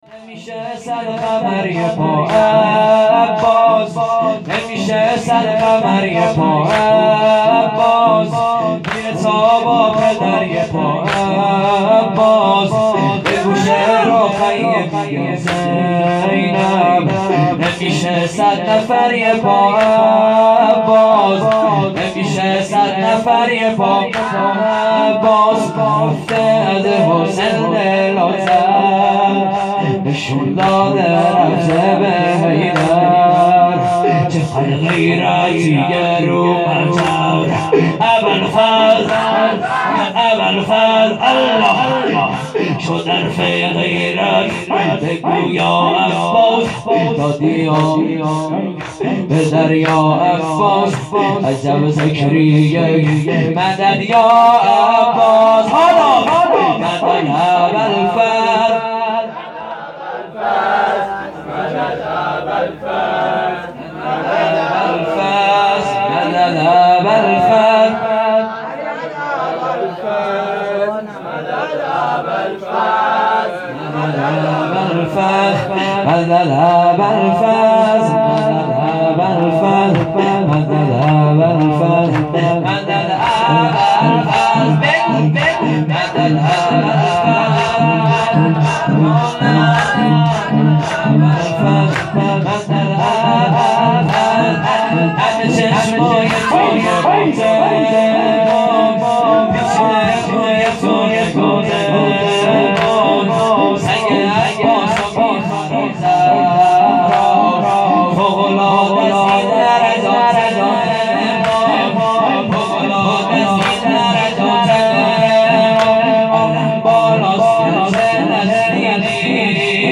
سه ضرب | اگه دیدی یلی بگو عباس | جلسه هفتگی 96/09/22
جلسه هفتگی 96/09/22